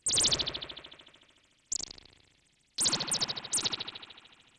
Baby Chicks.wav